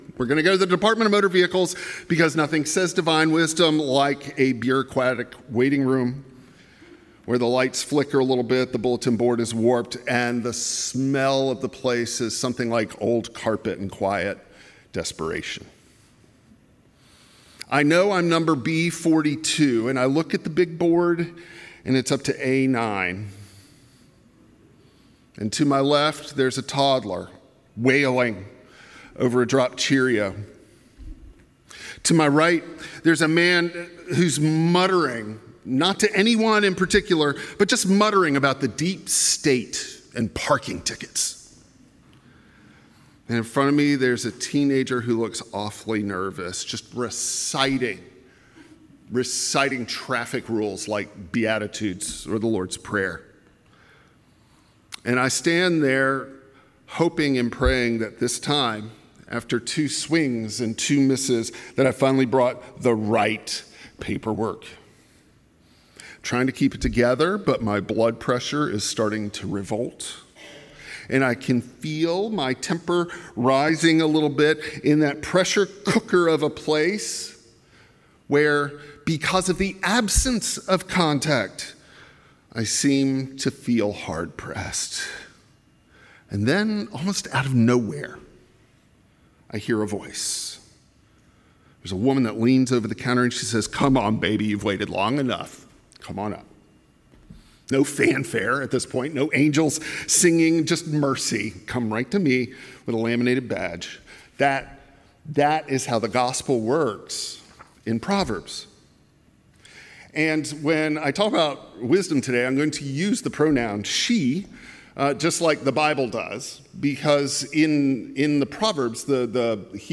Service Type: Traditional Service